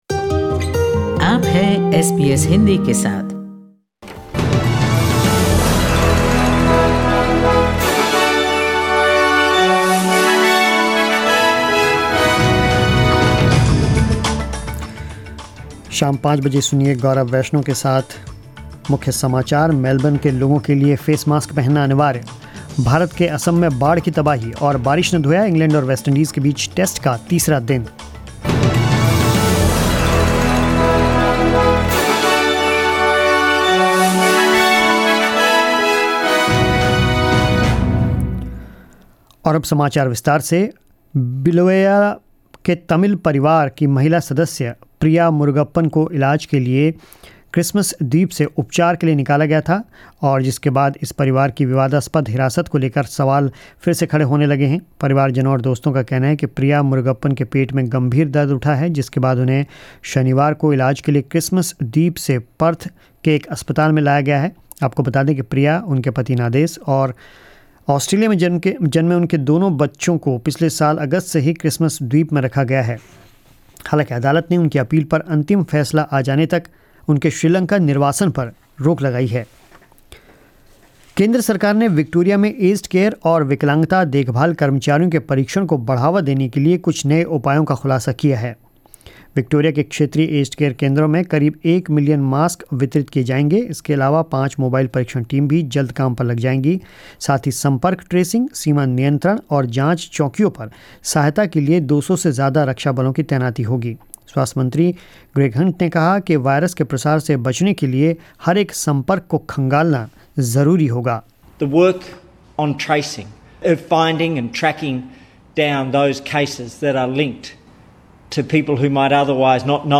News in Hindi 19 July 2020